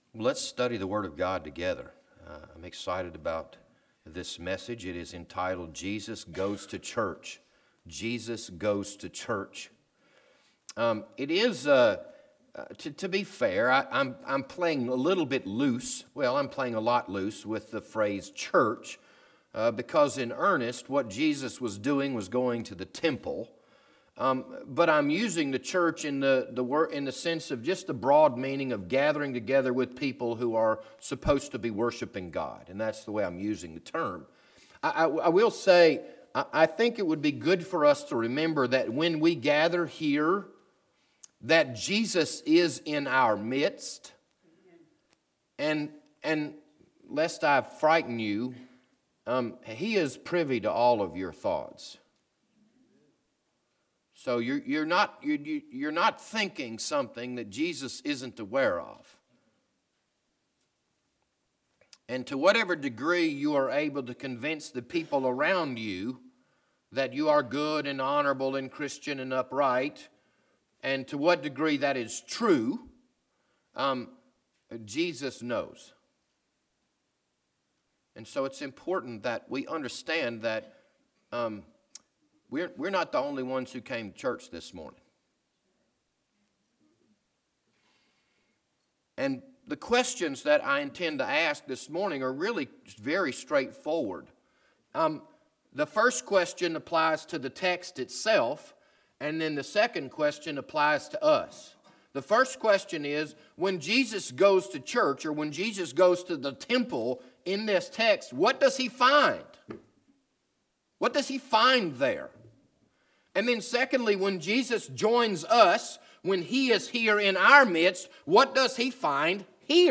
This Sunday evening sermon was recorded on March 10, 2019.